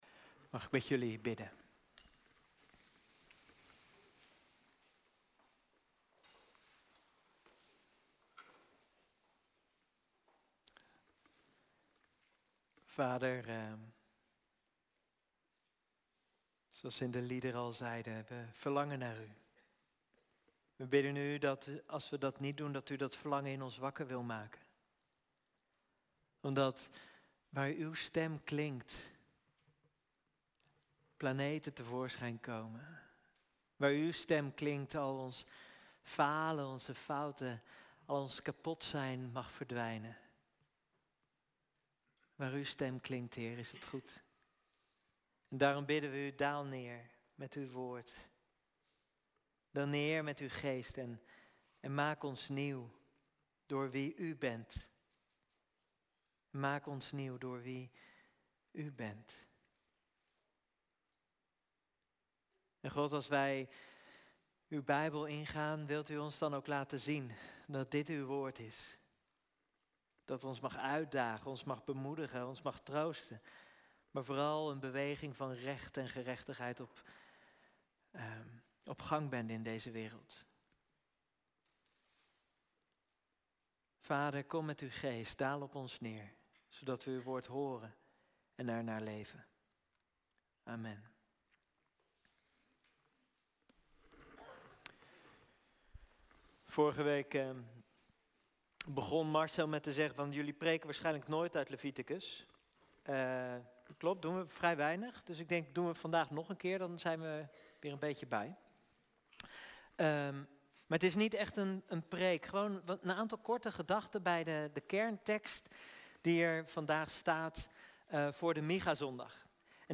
Kerdienst - 12 oktober 2025 - 2e dienst